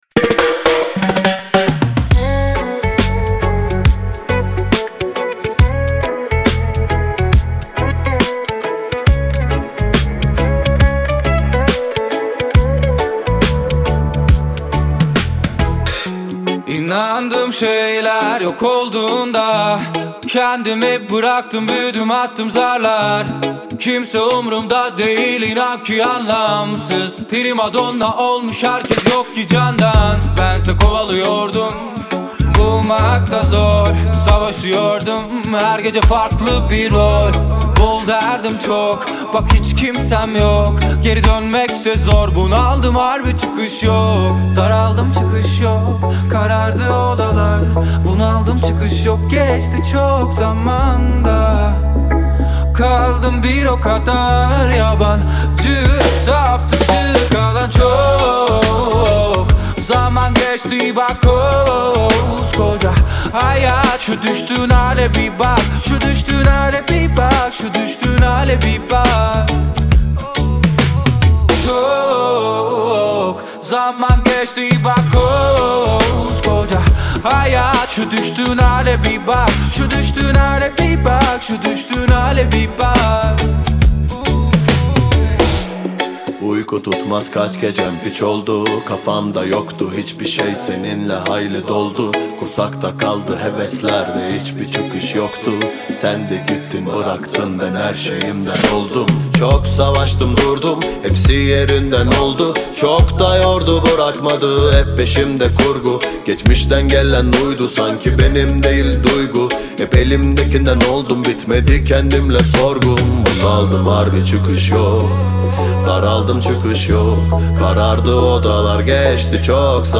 Трек размещён в разделе Турецкая музыка / Регги.